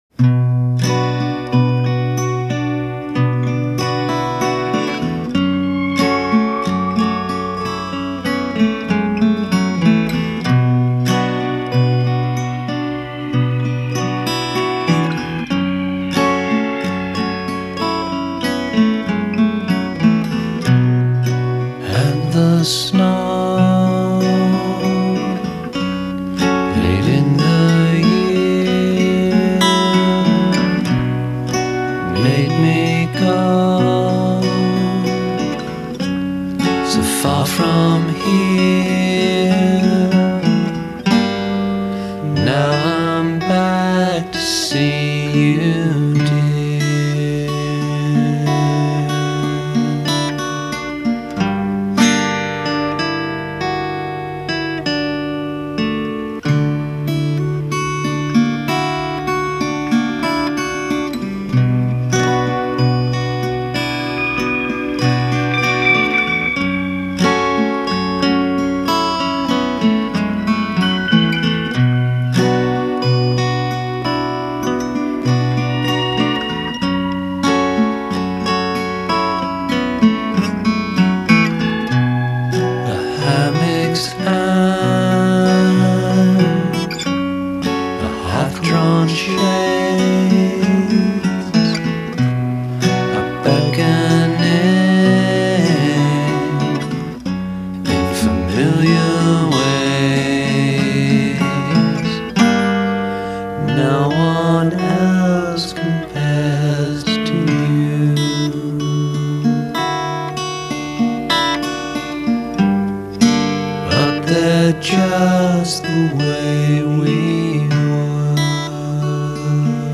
vocals and acoustic guitars
lap steel